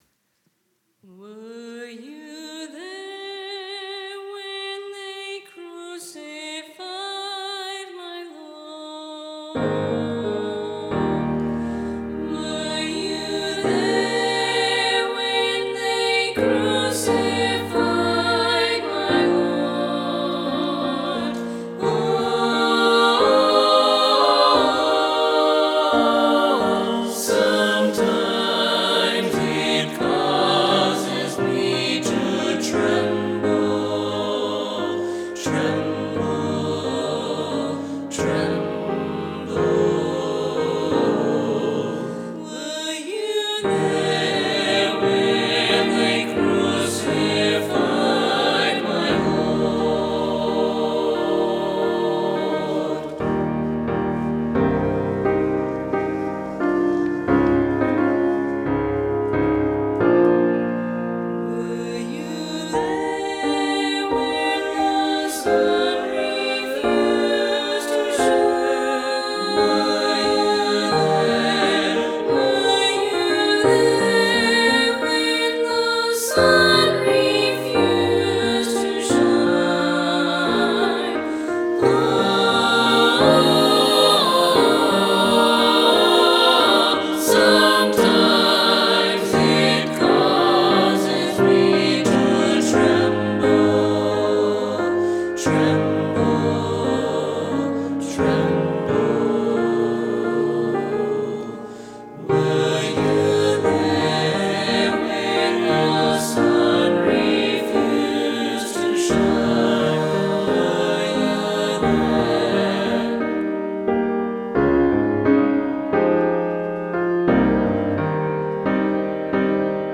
This new vision of the beautiful hymn Were You There? includes musical ideas from I Stand All Amazed. Written for an amateur church choir with singers from ages 10-60, this song is both accessible and beautiful!
Voicing/Instrumentation: SATB See more from Steve Danielson .